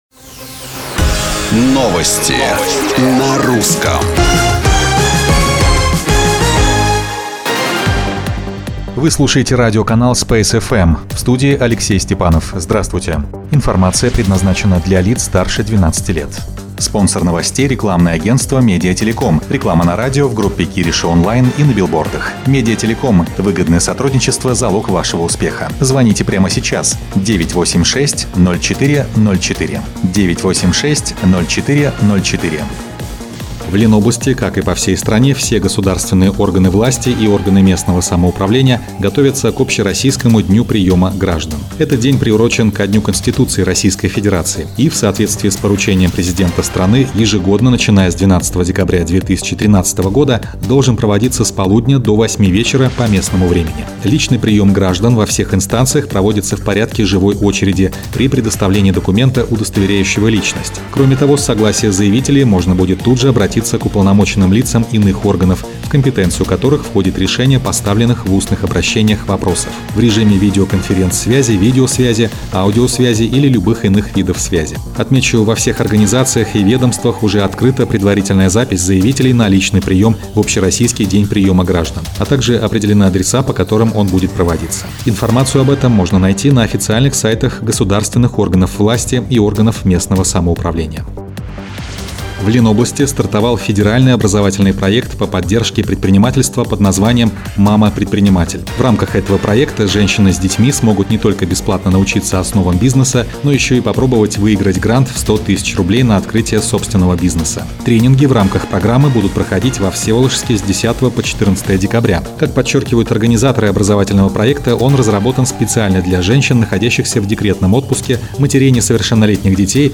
Новости Space FM 5.12.2018